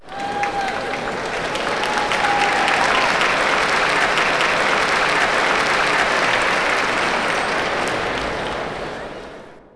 indoor
clap_052.wav